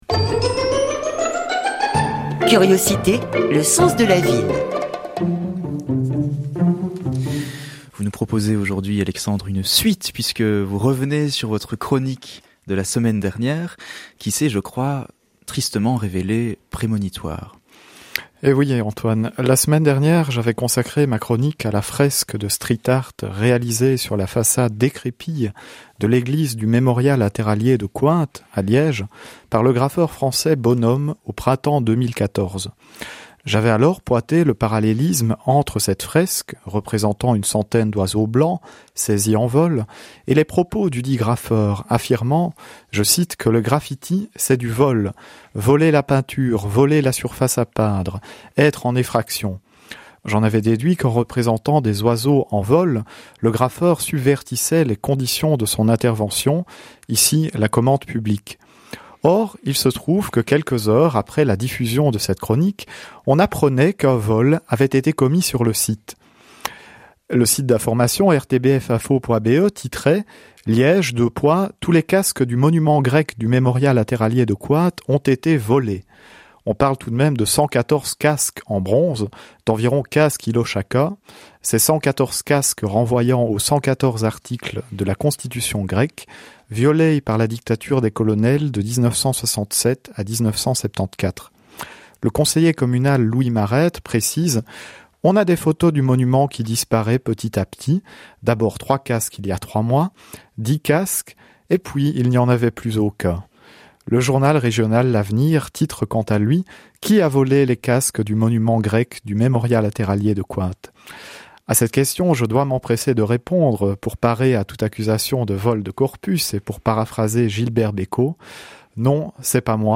chronique radio